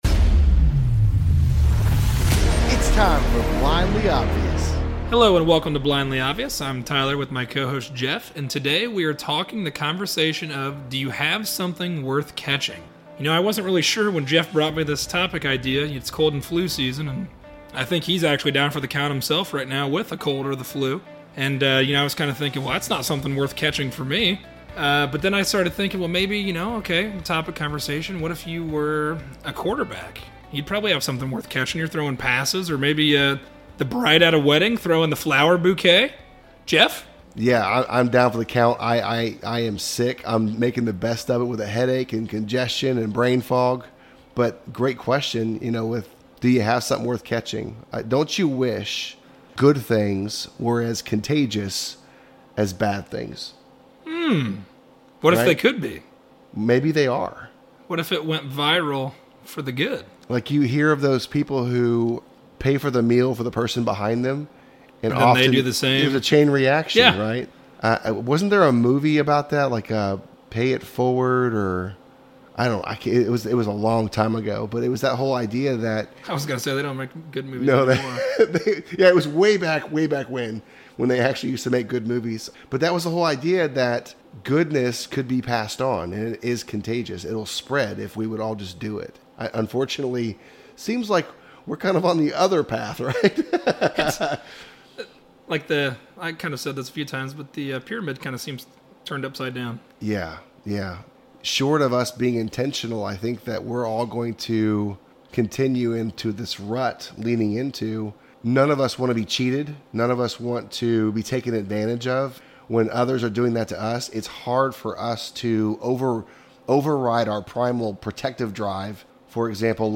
A conversation on something worth catching. Although there are many negative things that spread like wildfire, is there a contagion we can spread for good?